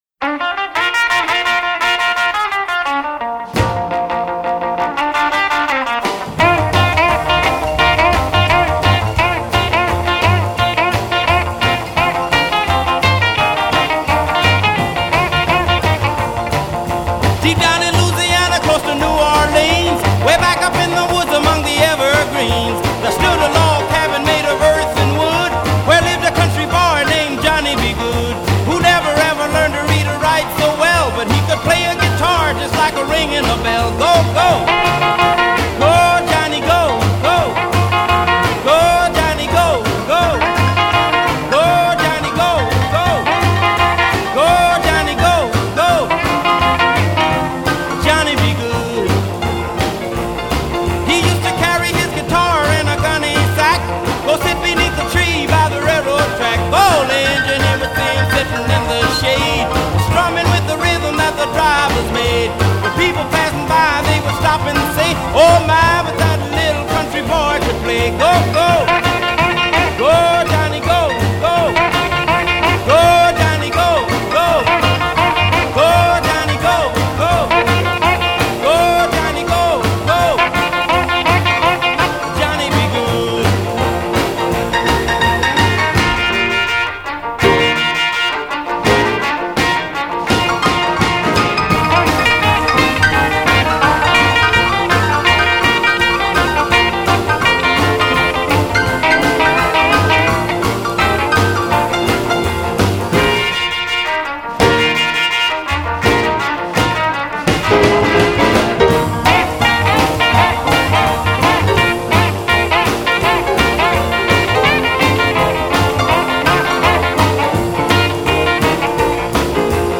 музыка гитара инет сайт